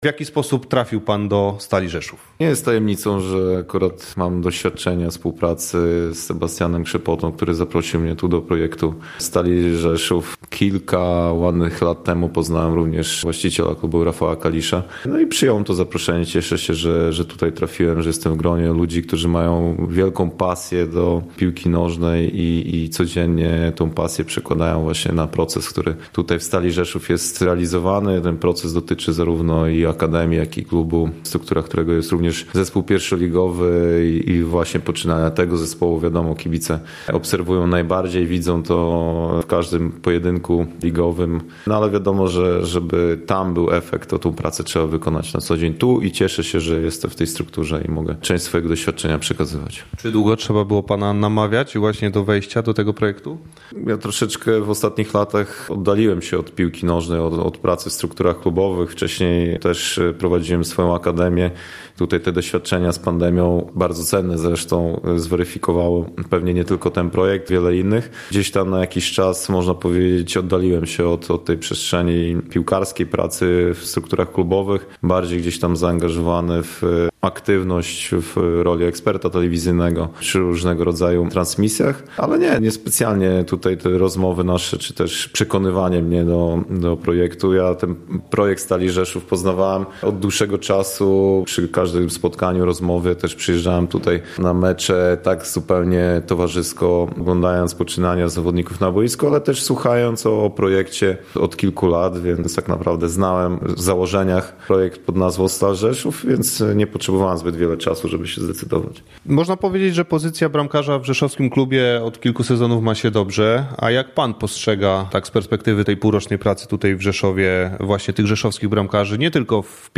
O jego roli w rzeszowskim klubie, a także o bogatej karierze zawodowej rozmawia z nim